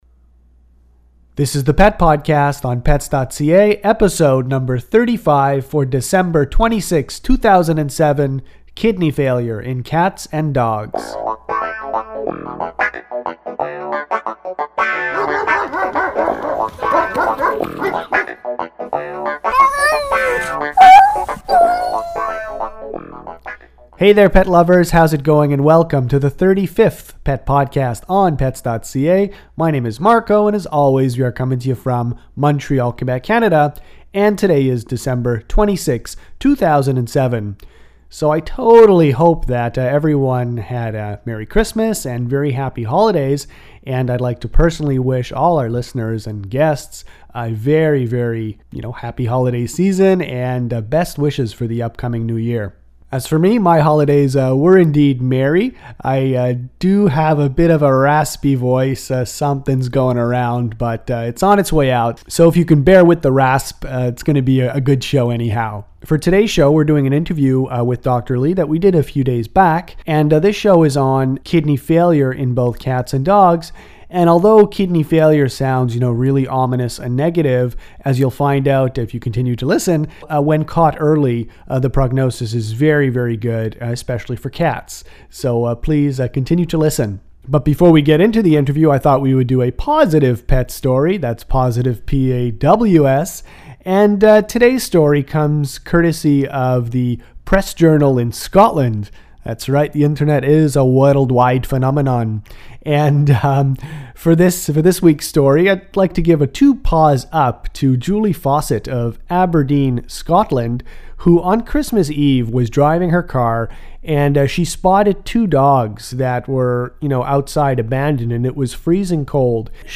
Pet podcast #35 features an interview